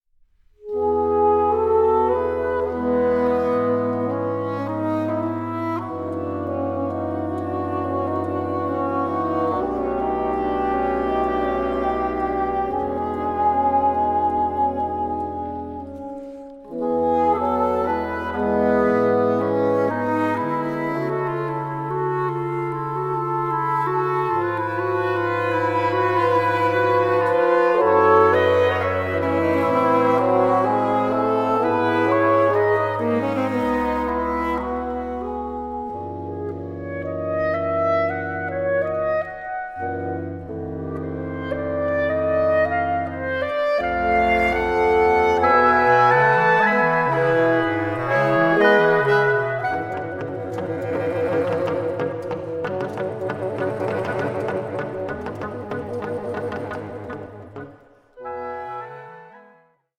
reed players